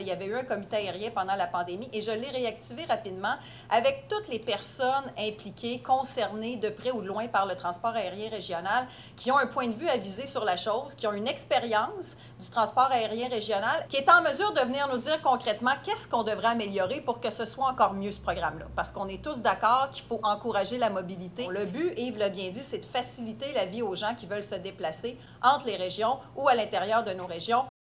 La ministre des Transports, Geneviève Guilbault, précise que cette version 2.0 du programme a été créée de concert avec les intervenants régionaux :